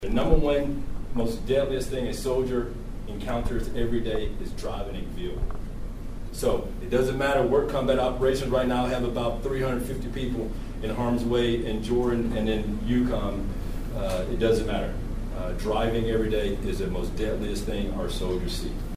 The Kansas Department of Transportation officially launched the statewide “Click It or Ticket” campaign Wednesday at Manhattan’s Peace Memorial Auditorium.